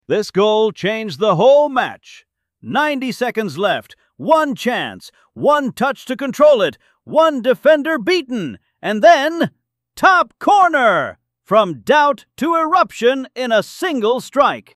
football commentary speech.mp3